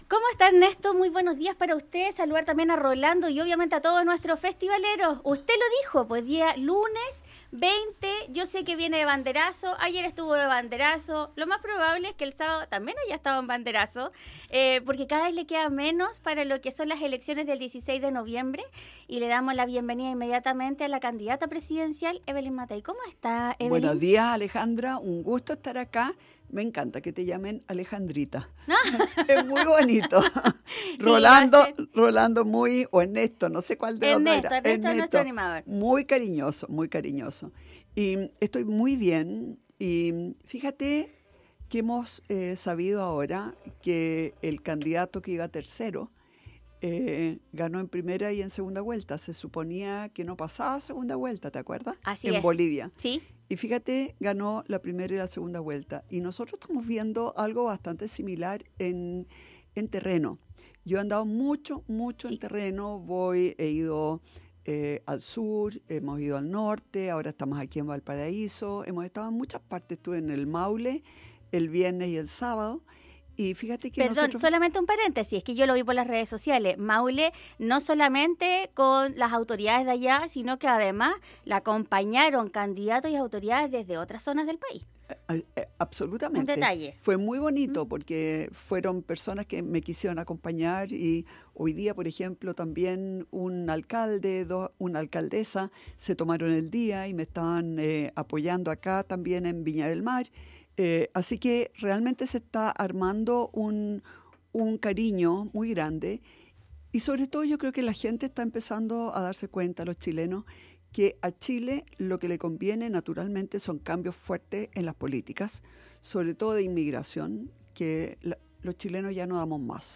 Candidata Presidencial Evelyn Matthei en los estudios de Radio Festival